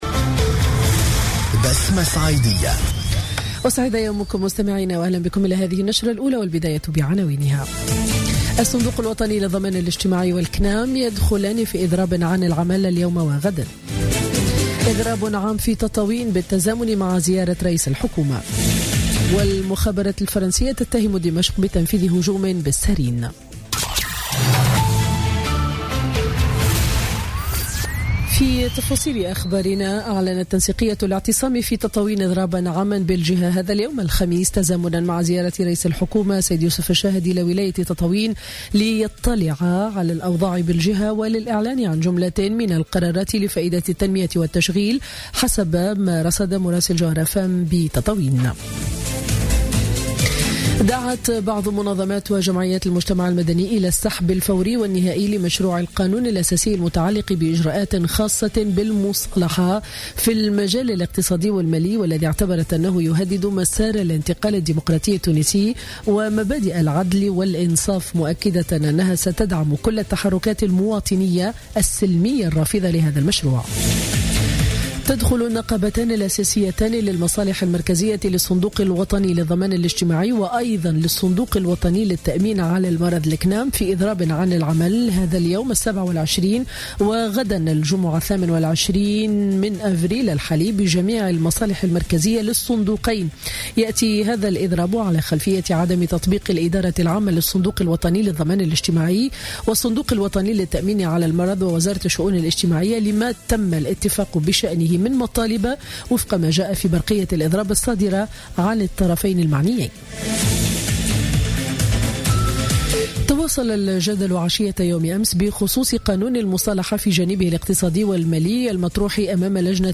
نشرة أخبار السابعة صباحا ليوم الخميس 27 أفريل 2017